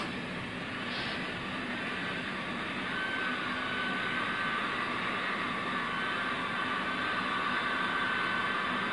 杂项特效 " 怪异的令人毛骨悚然的尖叫声/sfx
描述：奇怪的令人毛骨悚然的尖叫声/ sfx
Tag: 恐惧 怪异 悬疑 恐怖 鬼魅般出没 戏剧 幽灵 令人毛骨悚然 万圣节 邪恶 恐怖 怪异 FX SFX 惊险 恐怖